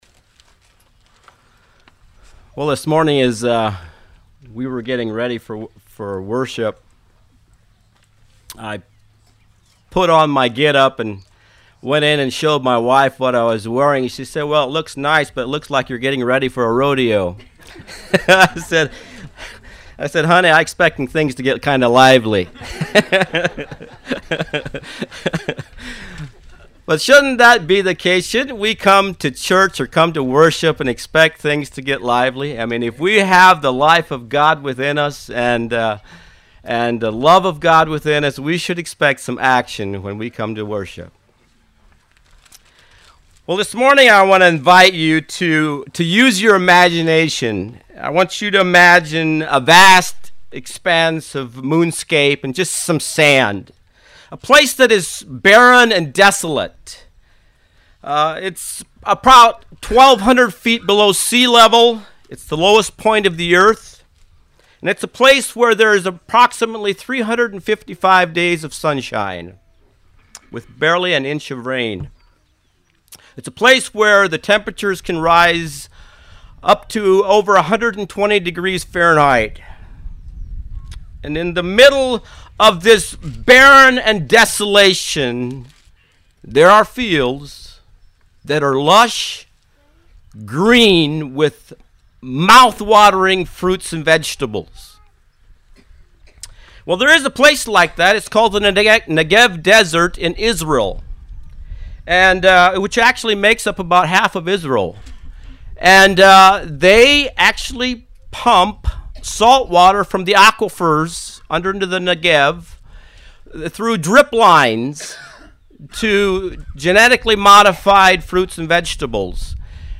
Sermons The Epistle of James